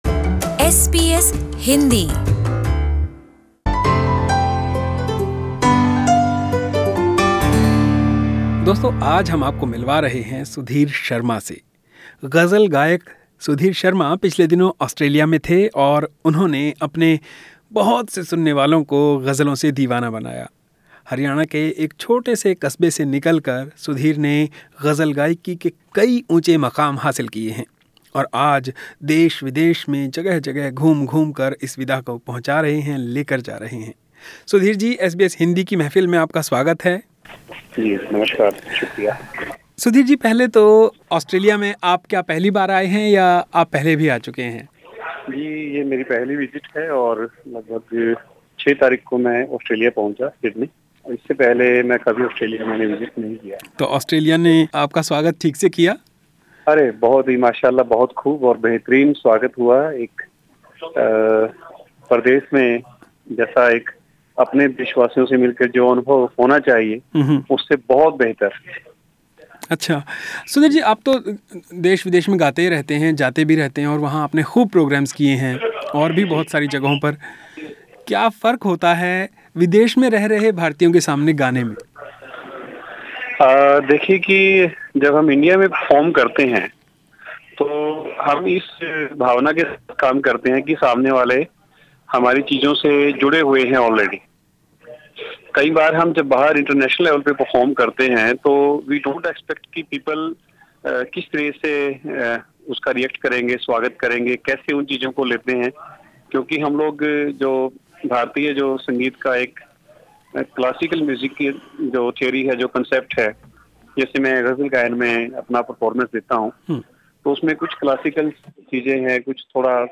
और एक गजल भी...